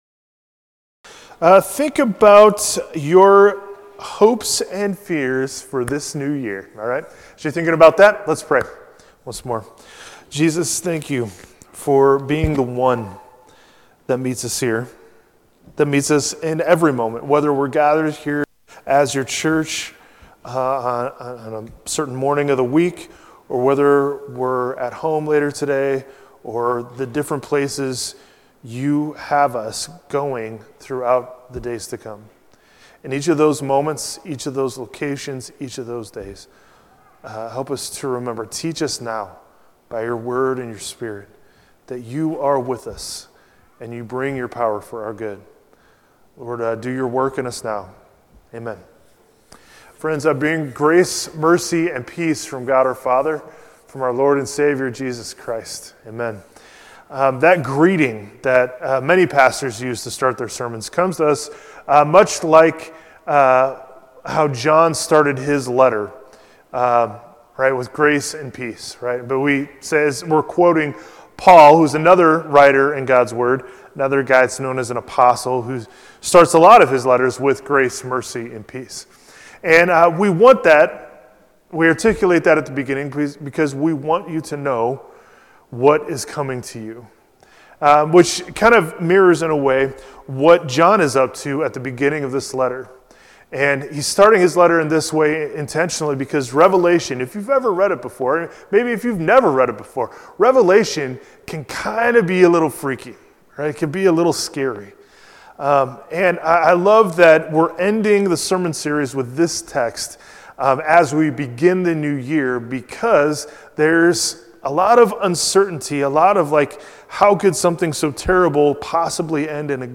jan-4-2026-sermon.mp3